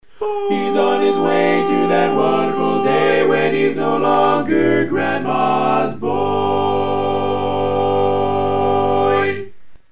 Key written in: G Major
Type: Barbershop